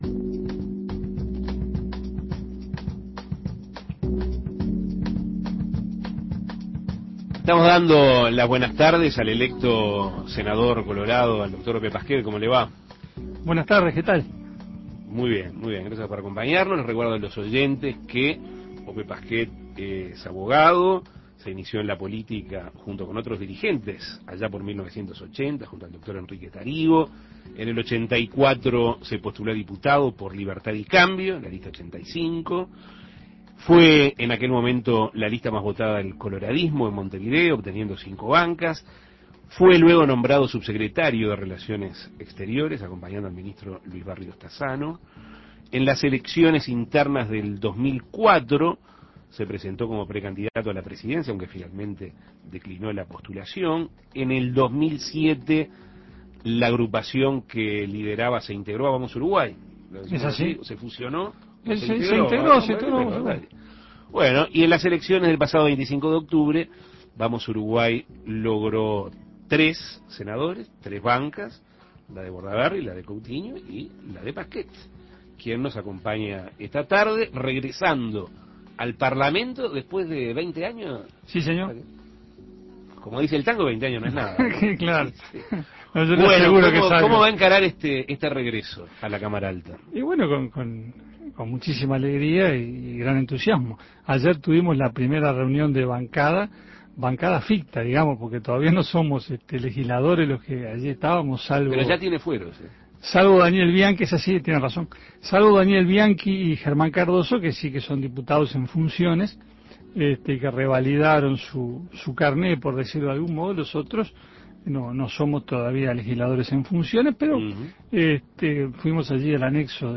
El recién electo senador por la Lista 10 del Partido Colorado, Ope Parquet, dialogó sobre lo que hará la agrupación política de aquí en adelante, del arsenal hallado en la vivienda del barrio Aires Puros y sobre su vuelta al Parlamento Nacional luego de dos décadas. Escuche la entrevista.